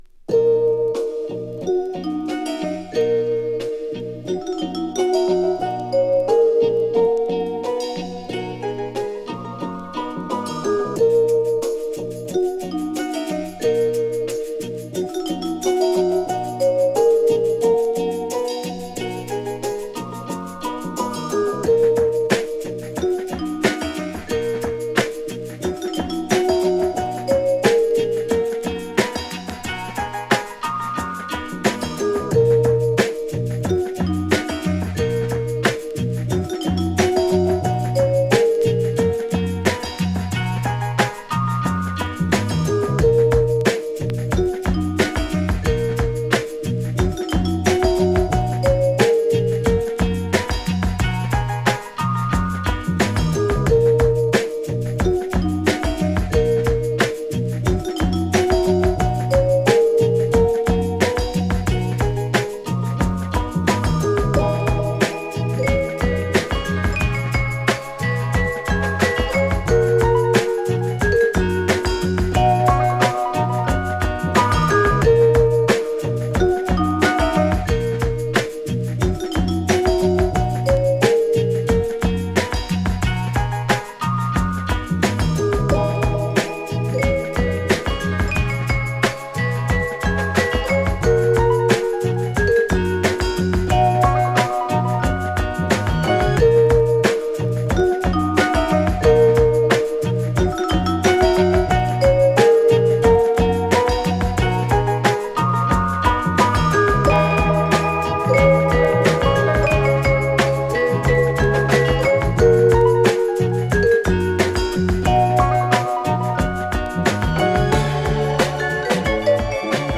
ヴィブラフォン、ストリングス、ピアノが柔らかなムードで巡るメロー・ジャズファンク
心地よい幻想感を巡らせるピアノやギタープレイが跳ねタメ効いたジャズファンクグルーヴに展開する